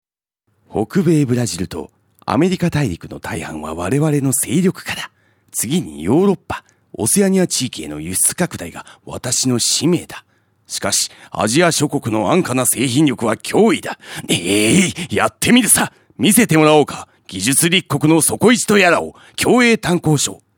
ラジオCM制作
有名アニメキャラクターを彷彿させる語り口で、壮大な世界観をラジオCMで表現。